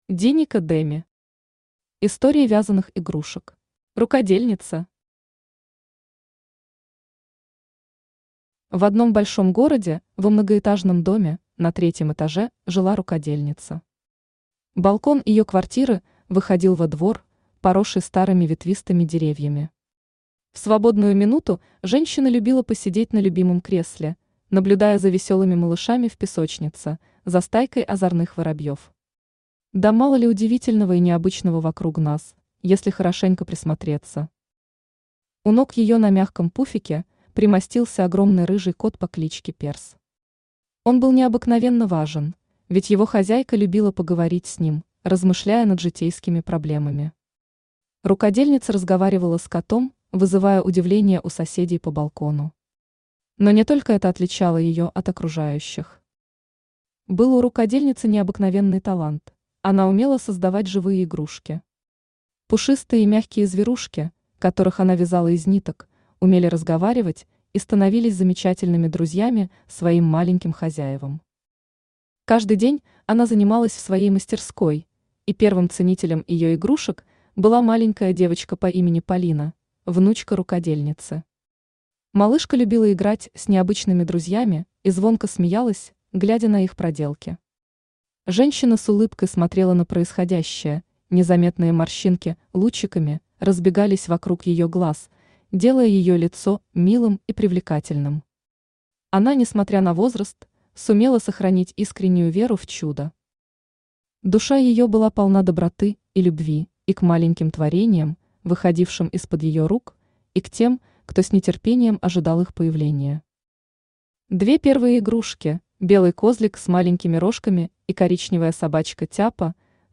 Аудиокнига Истории вязаных игрушек | Библиотека аудиокниг
Aудиокнига Истории вязаных игрушек Автор Диника Деми Читает аудиокнигу Авточтец ЛитРес.